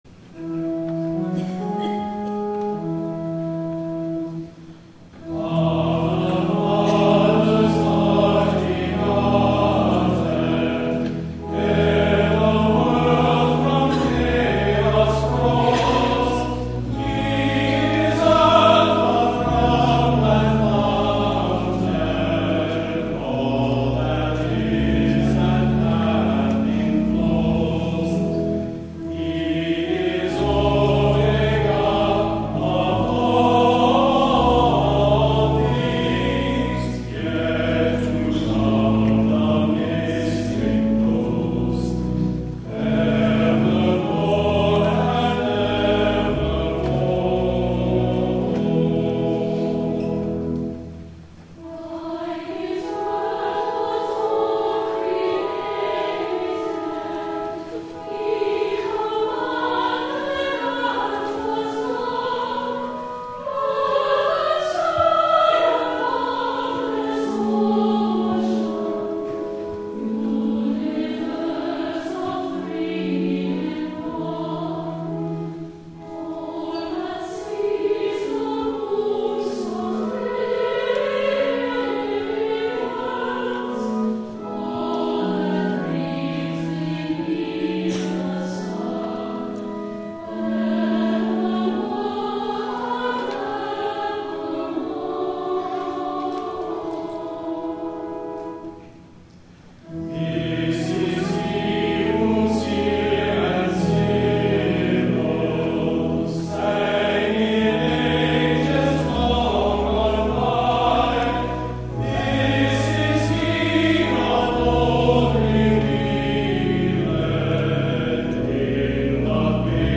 8 P.M. WORSHIP
THE CAROL